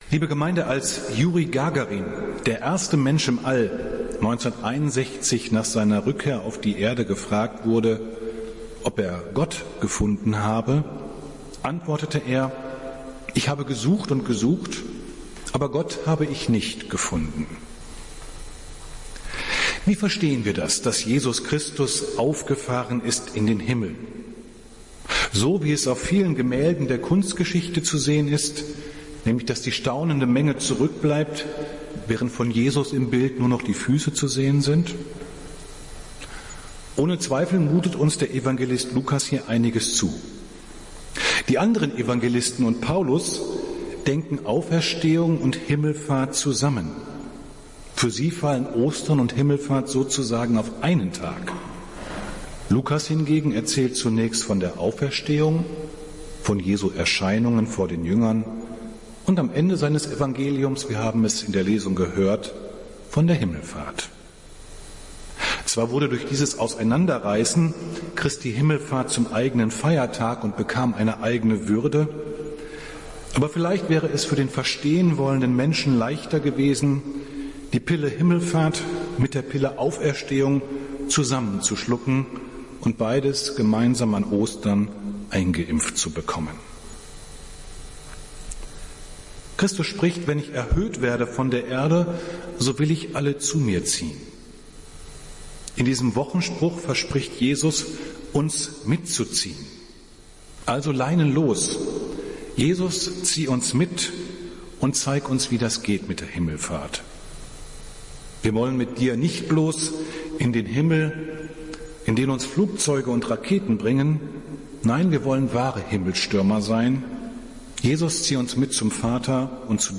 Predigt des Gottesdienstes zu Christi Himmelfahrt vom 21.05.2020